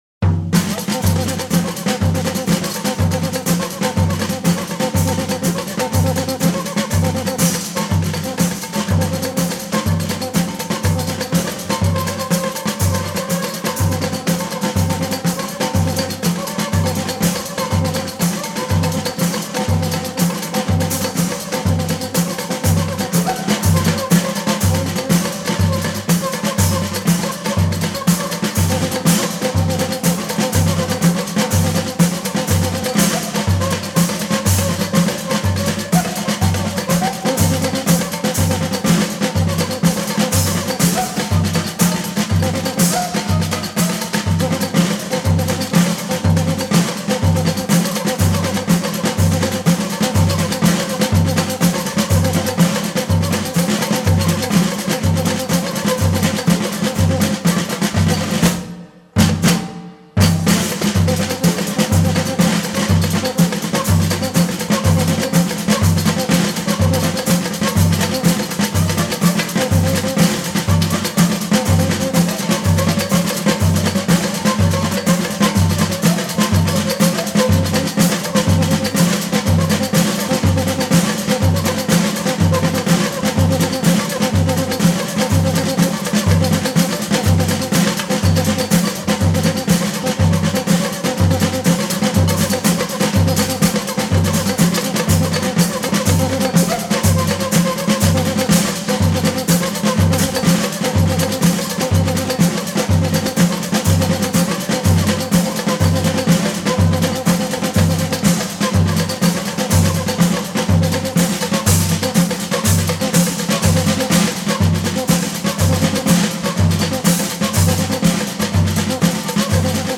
(Brazilian percussion)
batucada.mp3